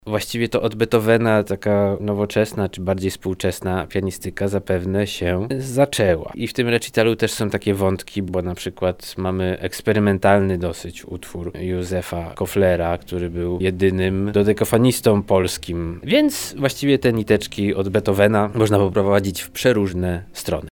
Relacja Fonie Lublina